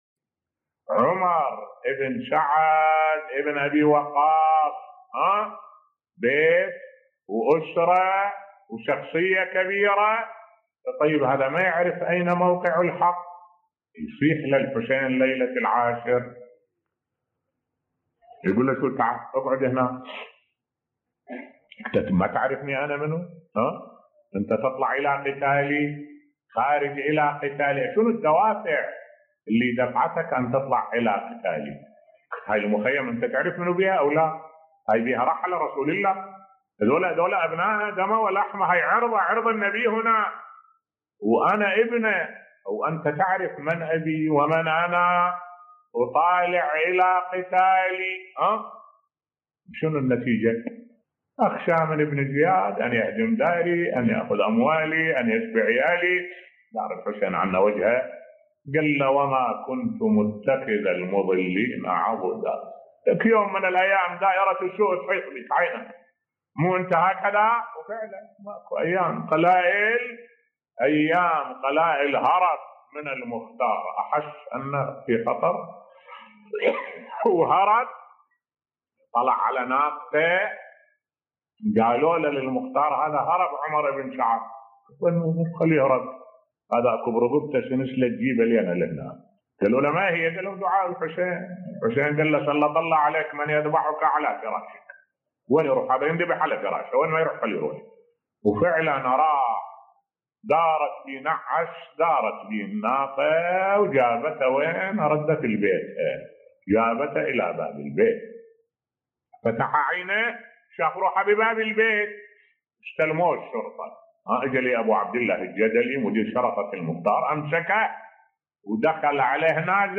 ملف صوتی اثر دعاء الامام الحسين (ع) في عاقبة عمر بن سعد بصوت الشيخ الدكتور أحمد الوائلي